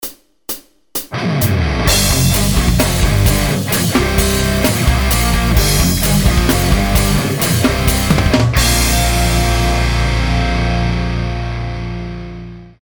Toto aj s podkladom:
kondik + dynamika bez sola